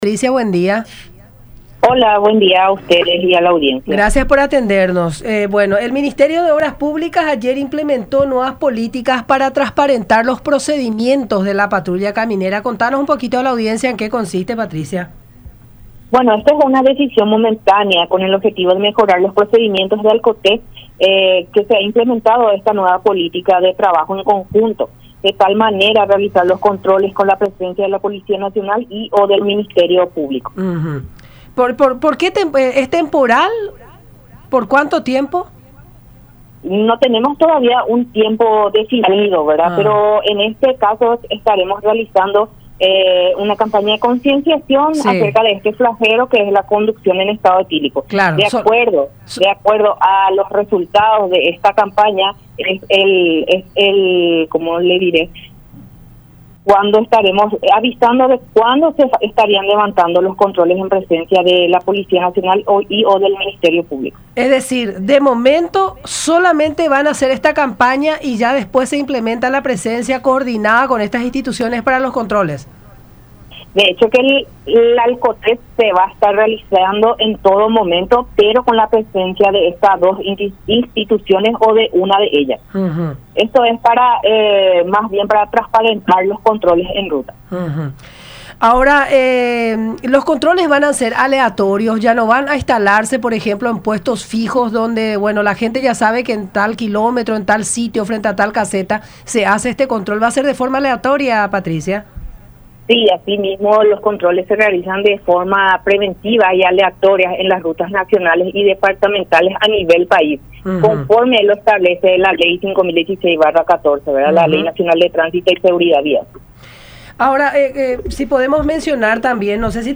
“Haremos una campaña de concientización sobre este flagelo que es conducir en estado etílico”, dijo en el programa “La Mañana de Unión” por radio La Unión y Unión Tv.